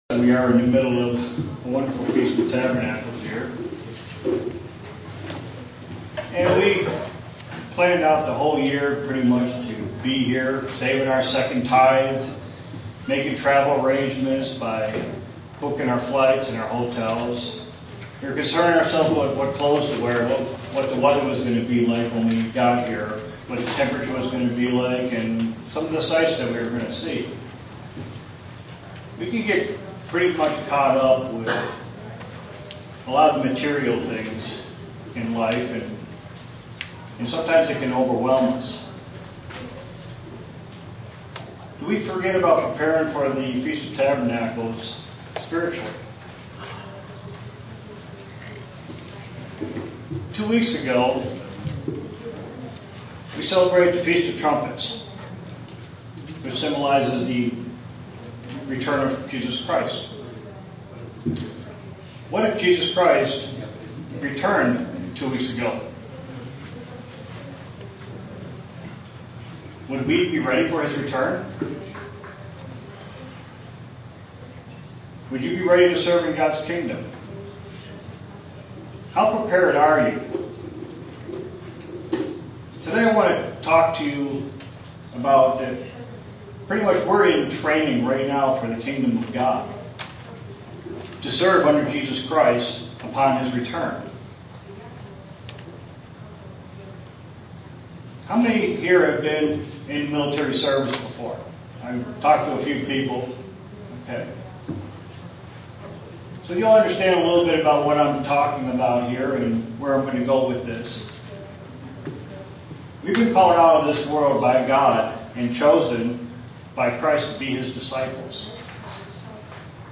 Day four of the Feast of Tabernacles in Estonia SEE VIDEO BELOW